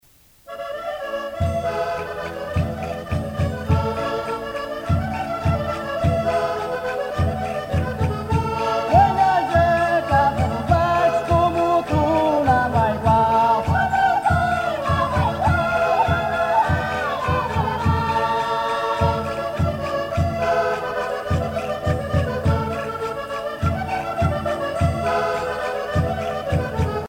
danse : vira (Portugal)
Grupo folclorico da Casa do Concelho de Arcos de Valdevez
Pièce musicale éditée